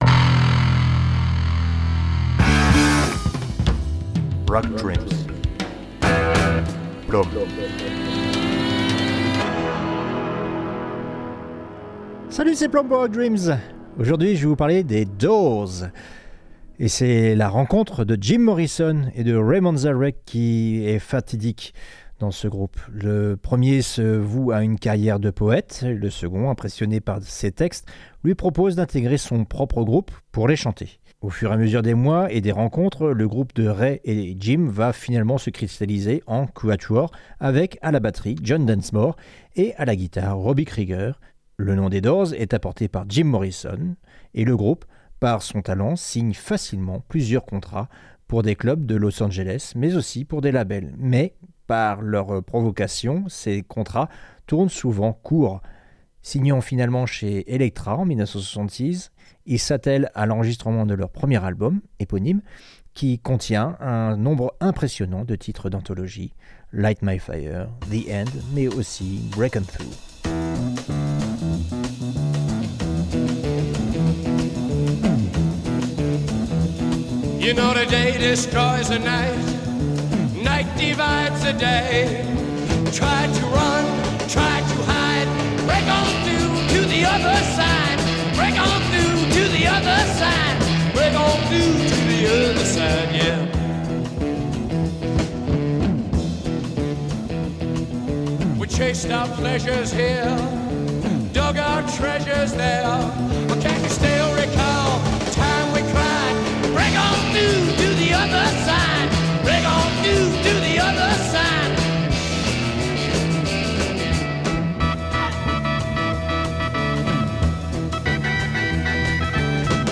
Rhythm n' Blues / Psyché